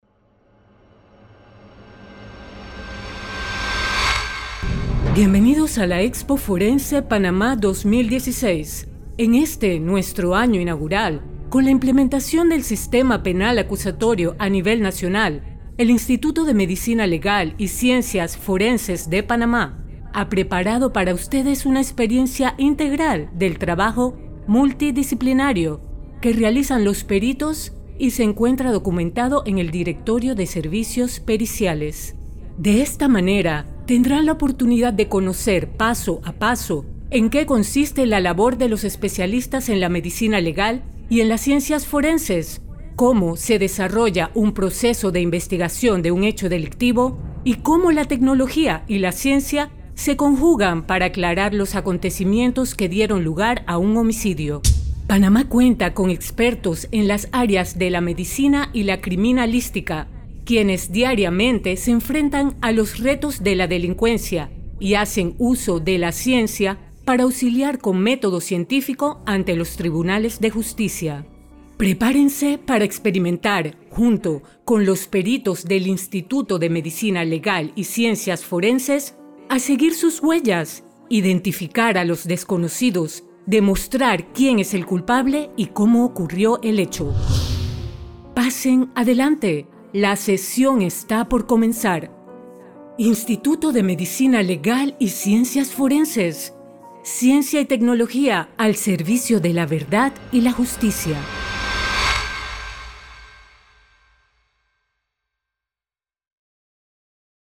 locutora de voz sensual,tono grave,medio y agudo,para publicidad,audiobooks, e learning,documentales,películas,otros.
kastilisch
Sprechprobe: Werbung (Muttersprache):